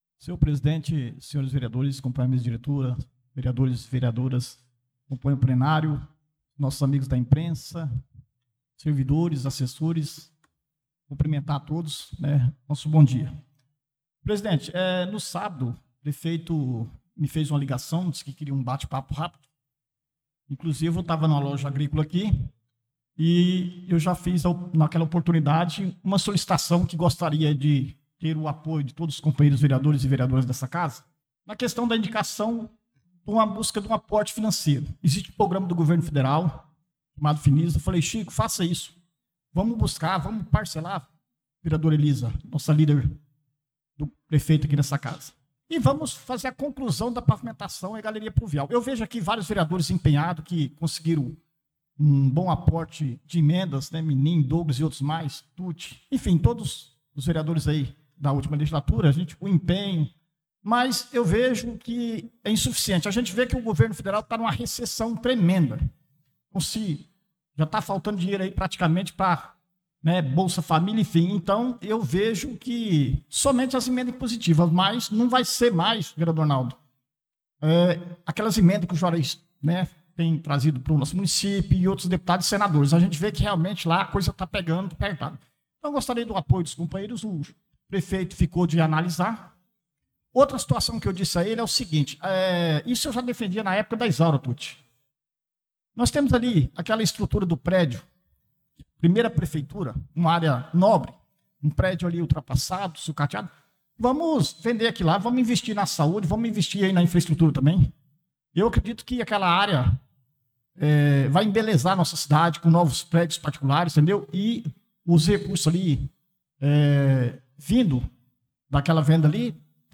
Pronunciamento do vereador Dida Pires na Sessão Ordinária do dia 04/02/2025